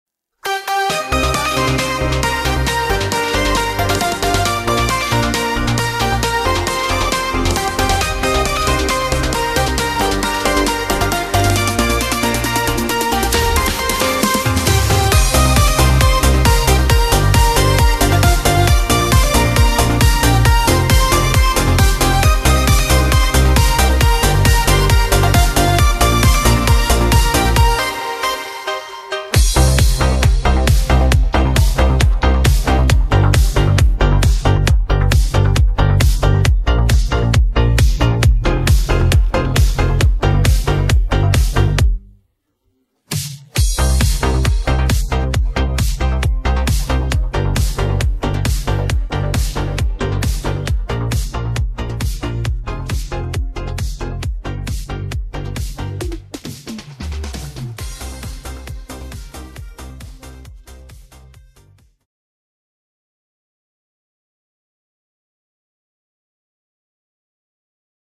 минус